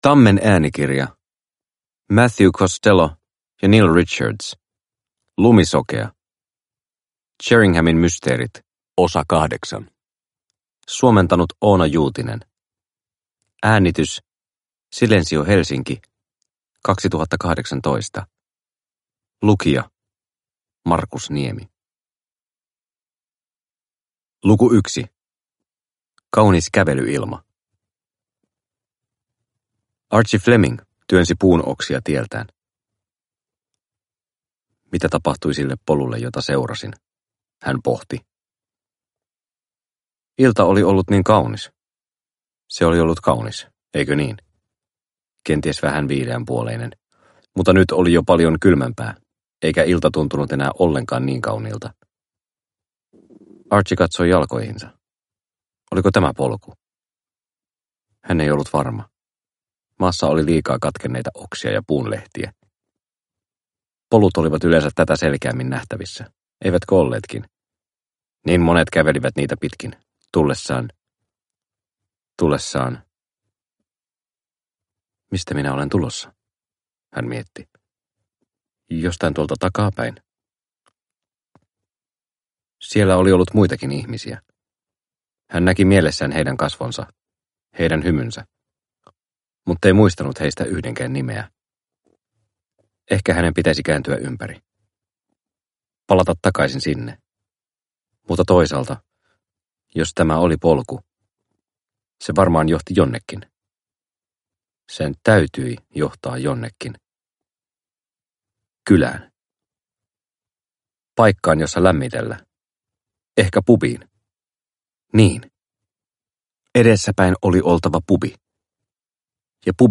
Lumisokea – Ljudbok – Laddas ner